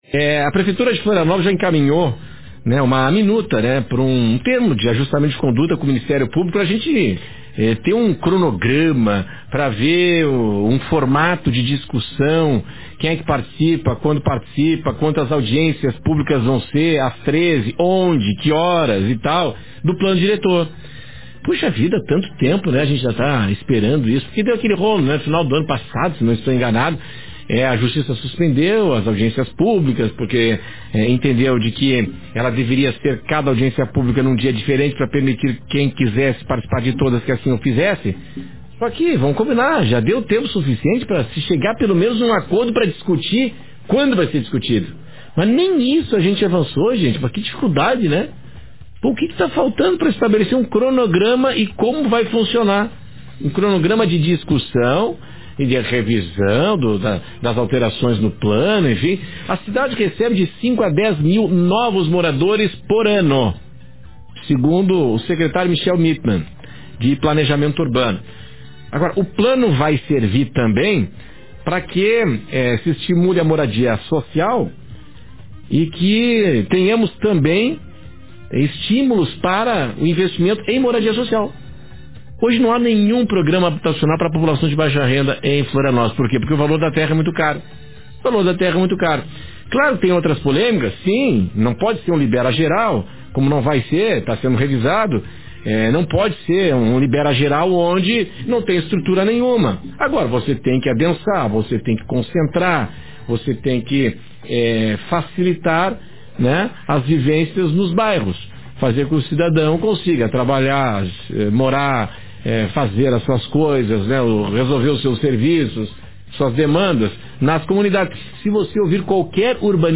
Confira o comentário